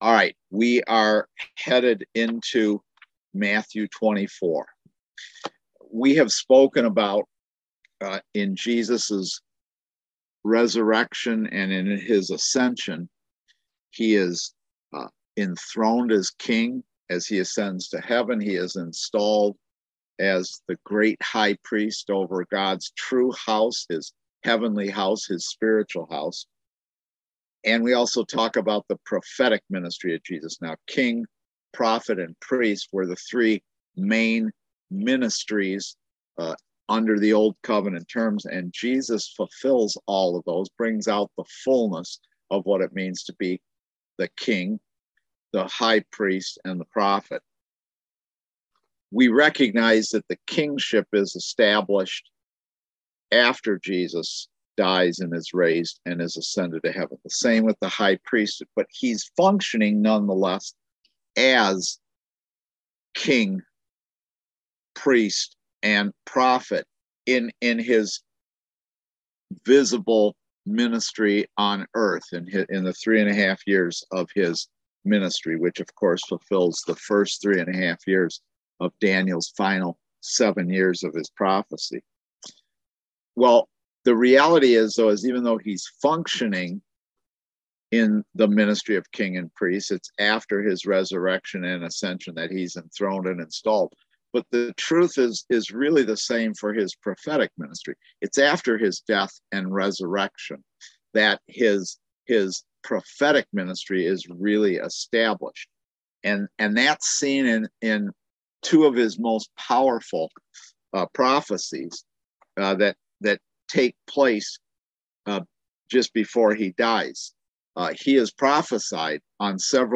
Series: Eschatology in Daniel and Revelation Service Type: Kingdom Education Class